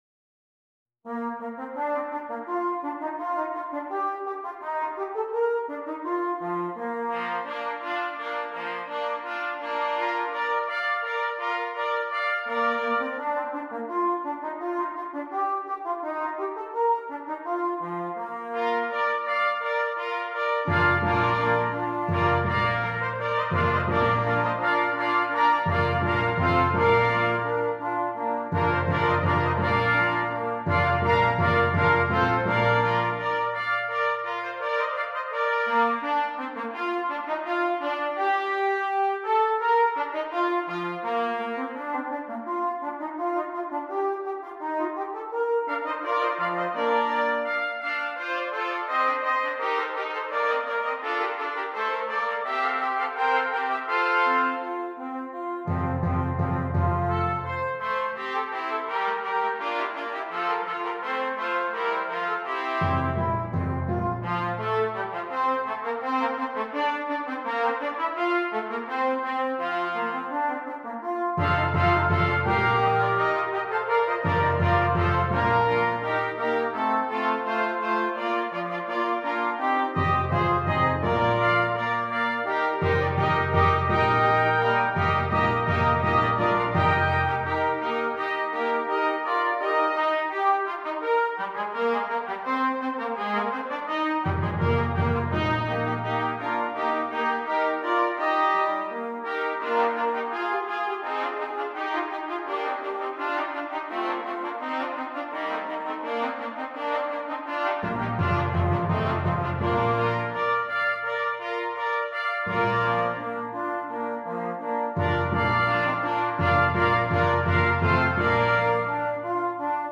Brass
6 Trumpets